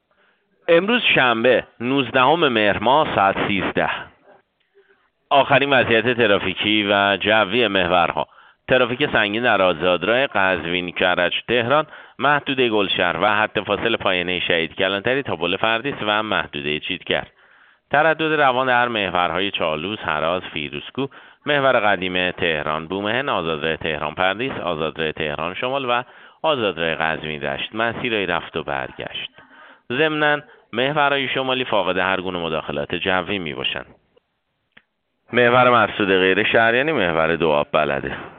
گزارش رادیو اینترنتی از آخرین وضعیت ترافیکی جاده‌ها ساعت ۱۳ نوزدهم مهر؛